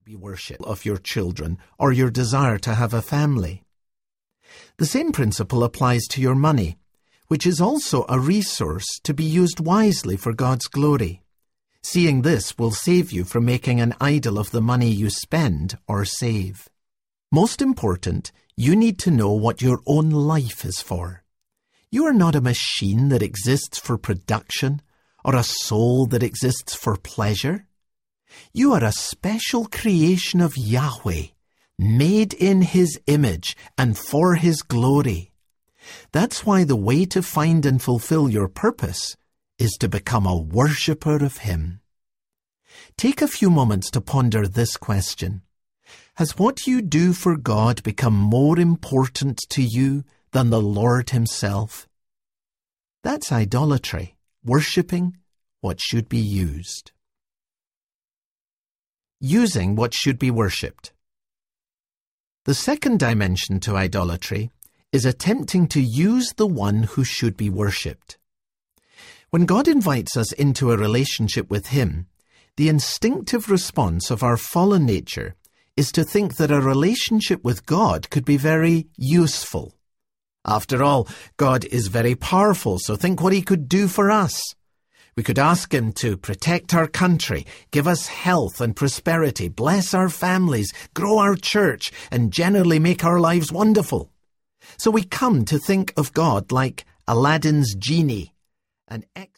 The 10 Greatest Struggles of Your Life Audiobook
4.38 Hrs. – Unabridged